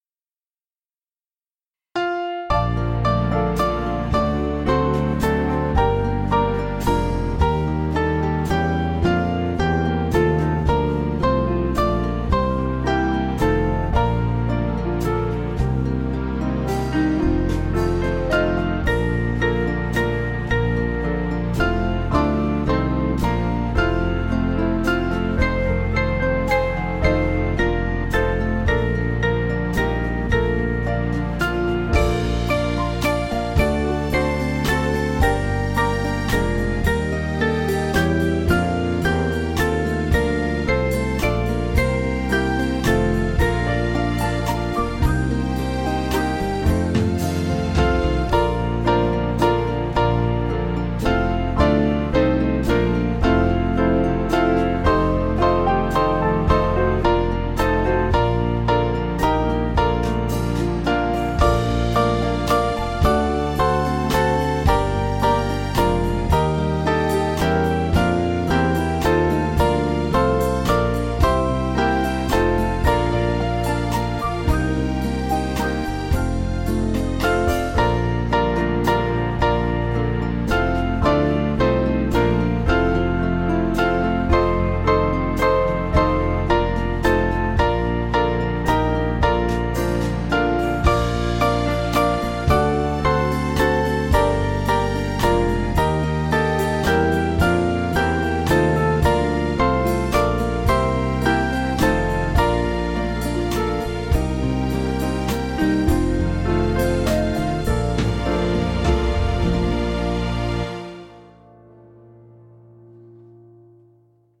Small Band
(CM)   3/Bb 574.6kb